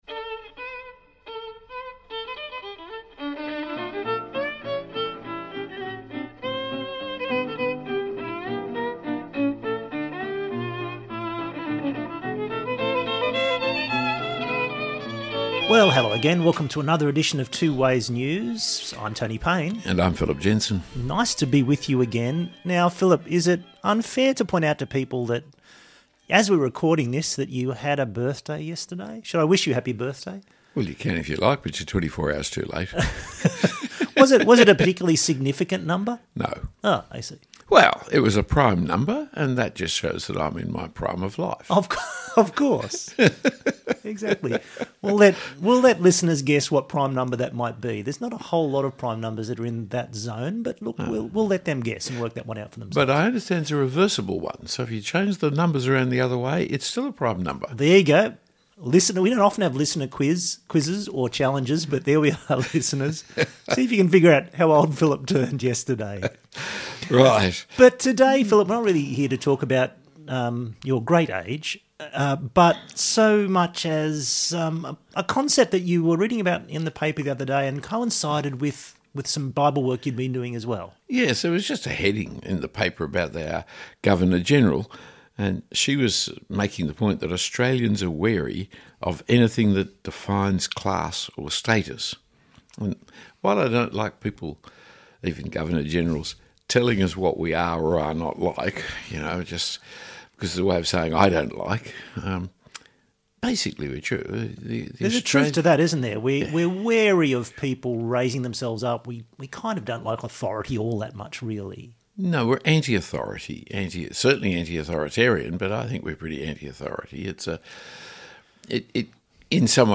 Discussing ‘submission’ and why we tend to recoil from it in most of its forms takes the conversation in this episode to some massive subjects: power, authority, politics, Marxism, capitalism, equality and even singing.